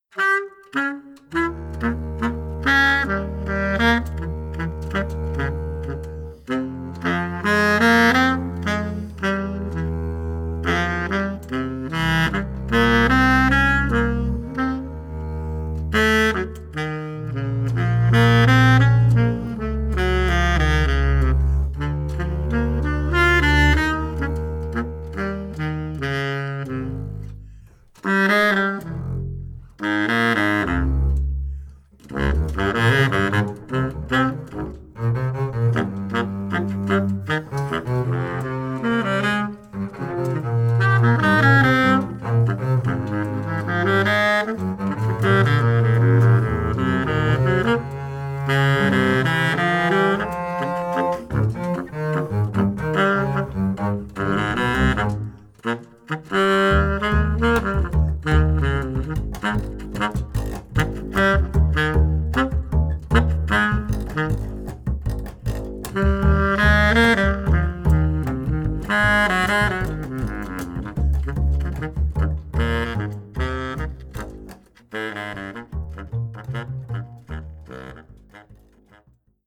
Baritonsaxophon
Kontrabass